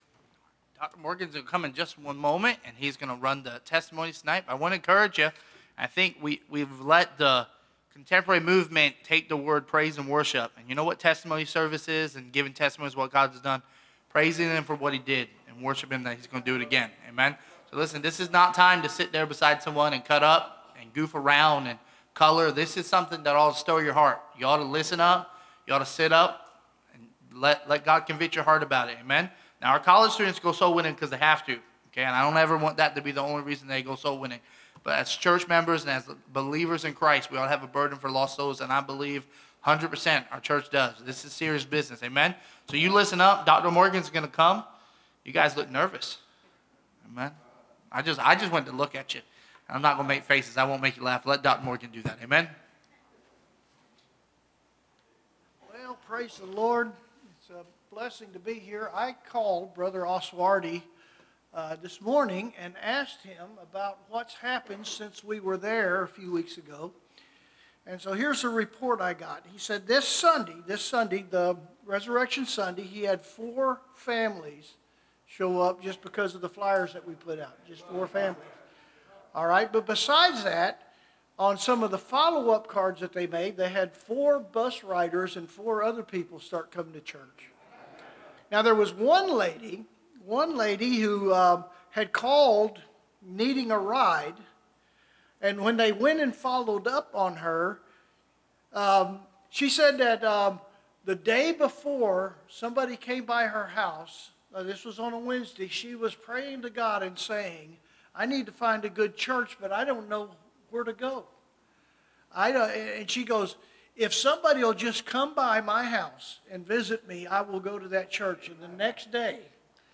Series: Soul Winning Marathon 2026 Testimonies